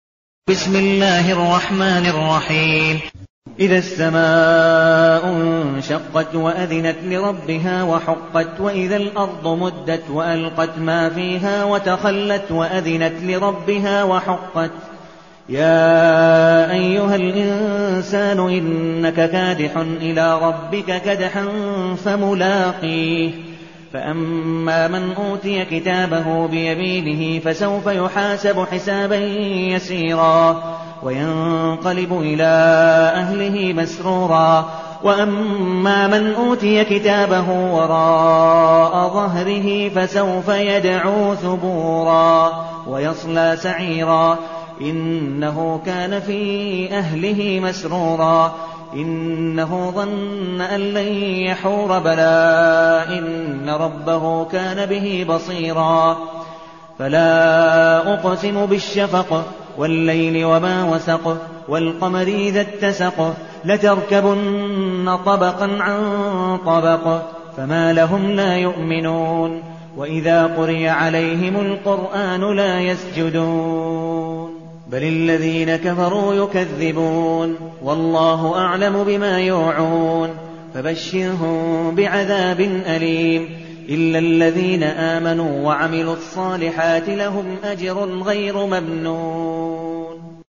المكان: المسجد النبوي الشيخ: عبدالودود بن مقبول حنيف عبدالودود بن مقبول حنيف الانشقاق